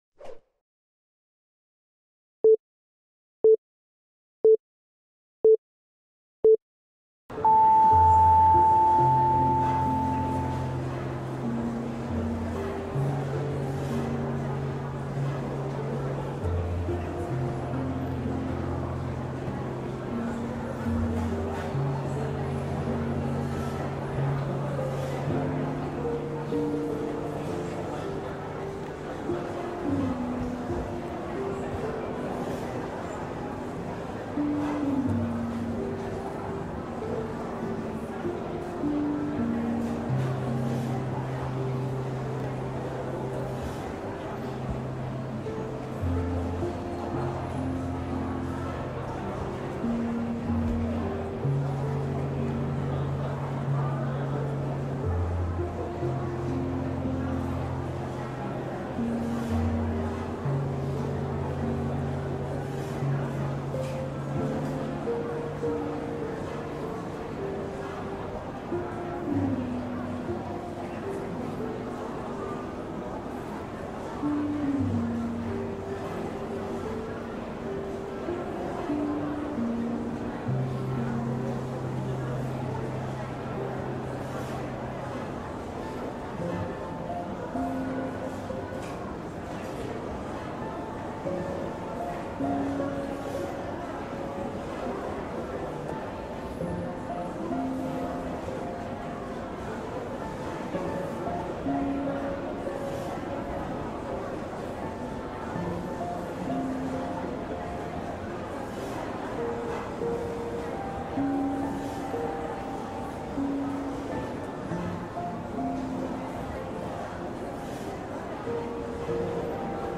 自然治癒音で仕事 | 自然のアンビエント音で集中と記憶力を高める
雨が静かに窓を叩くように、雨の音が思考を包み込む。
目を閉じれば広がる、木々の揺れる音、風に溶ける水のせせらぎ、夜空にひっそりと響く虫の声。